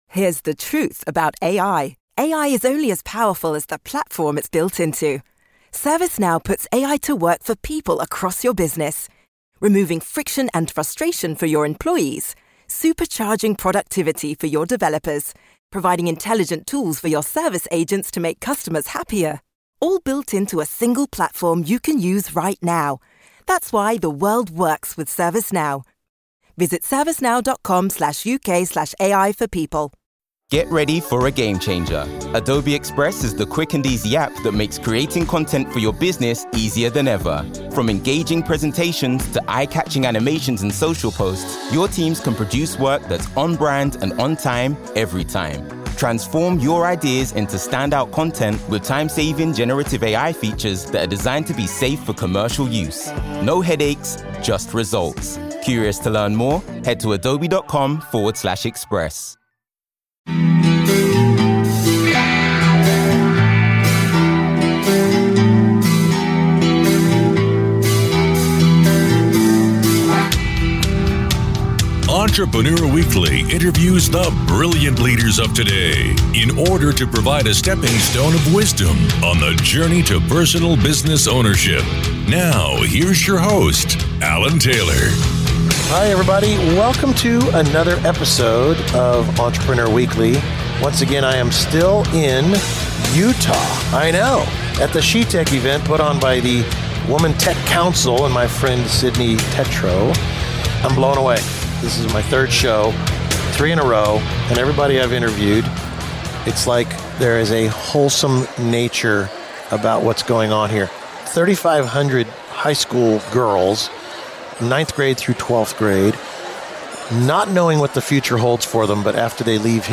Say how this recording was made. SheTech Event 2025 Mountain America Expo Center III